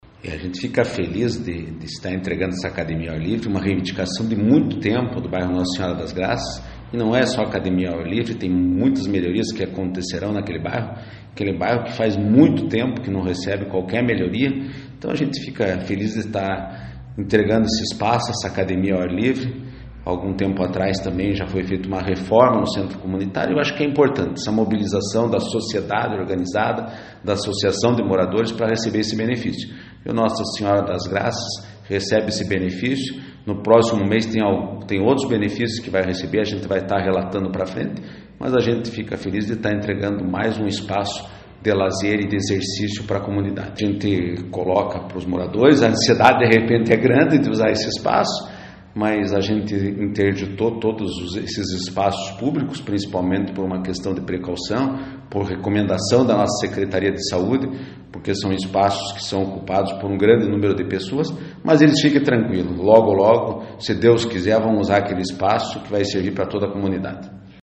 O vice-prefeito Bachir Abbas, fala deste pedido da população do bairro Nossa Senhora das Graças e confirma que em breve outros pedidos dos moradores serão iniciados.